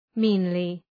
Shkrimi fonetik {‘mi:nlı}